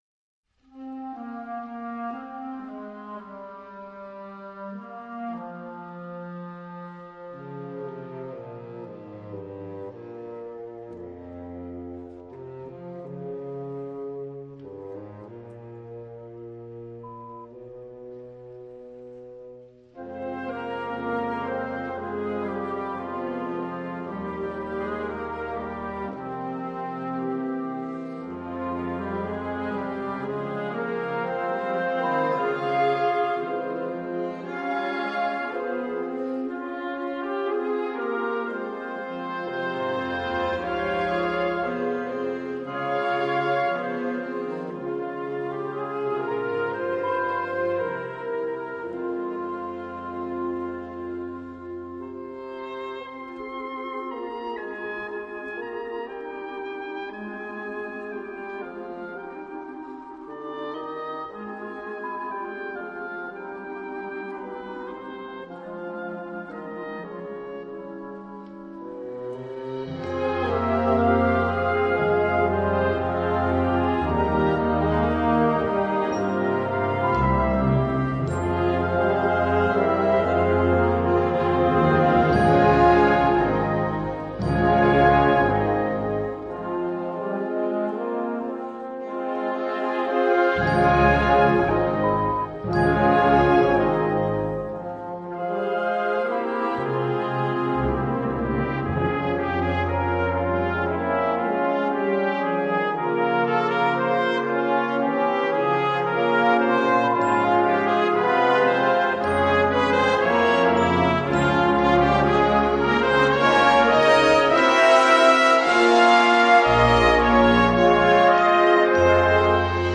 Besetzung: Blasorchester
gefühlvolle Ballade
Blasorchester